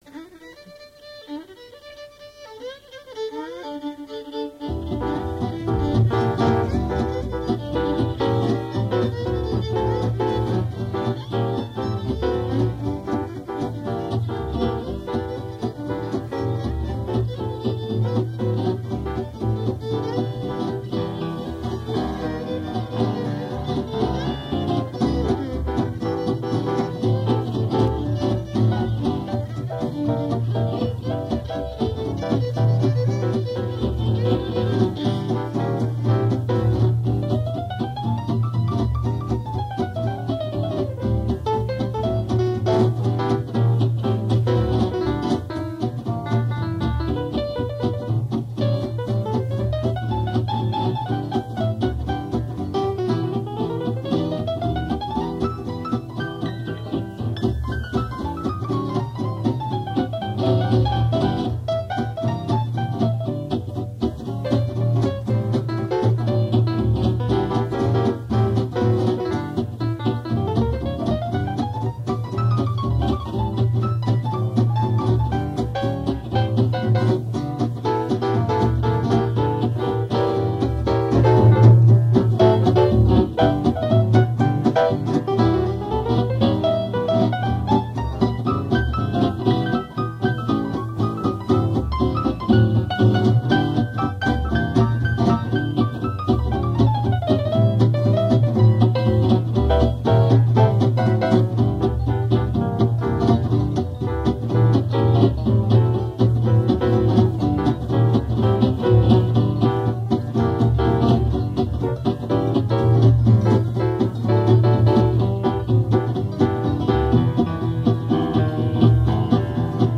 Rétrospective du  jazz de 1955 à 1998